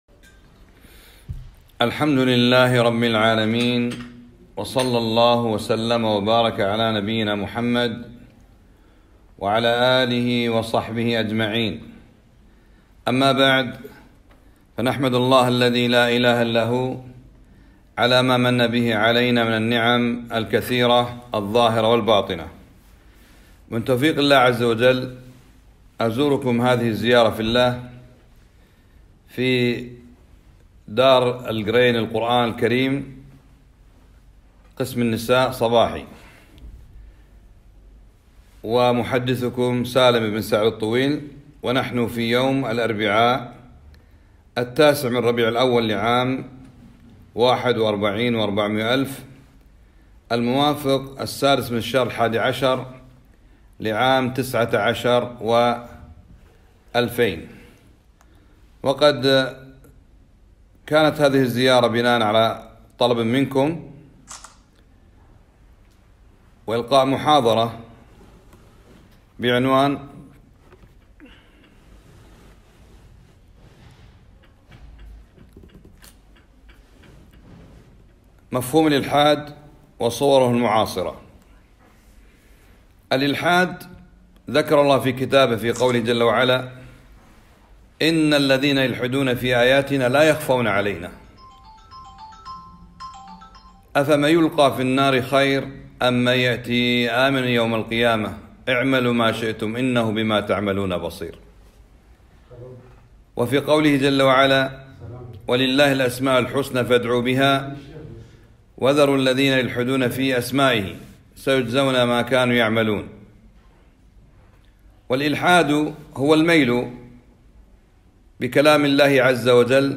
محاضرة - مفهوم الالحاد و صورة المعاصرة - دروس الكويت